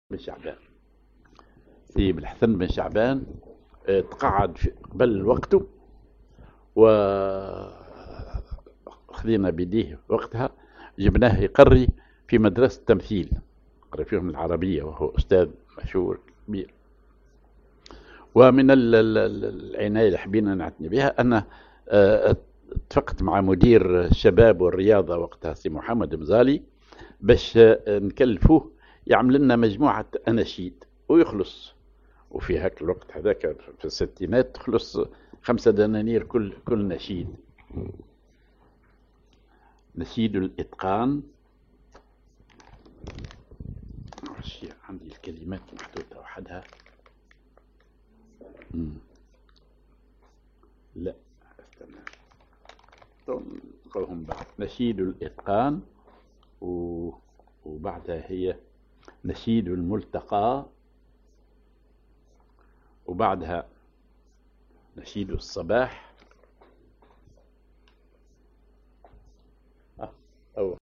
Maqam ID حجاز
genre نشيد